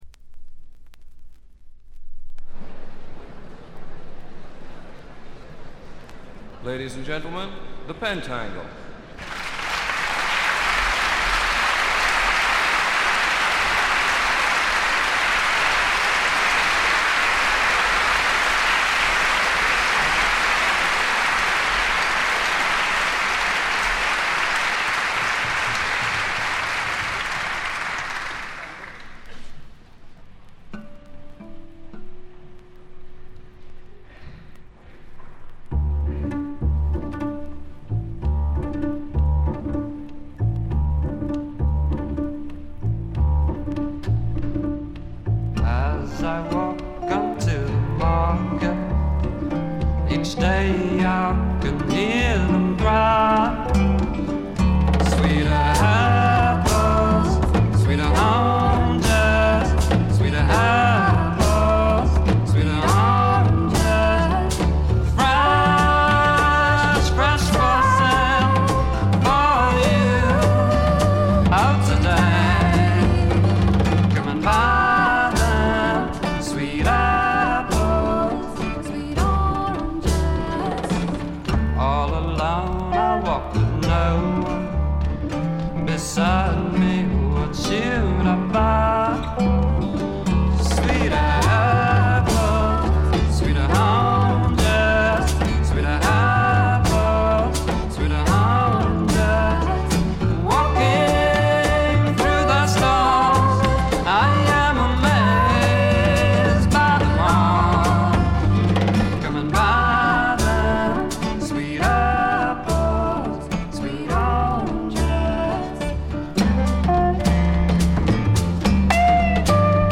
ホーム > レコード：英国 フォーク / トラッド
他はほとんどノイズ感無しで良好に鑑賞できます。
デビュー作発表後時を経ずしてリリースされた2枚組作品で、ライヴとスタジオがそれぞれ1枚づつ収録されています。
試聴曲は現品からの取り込み音源です。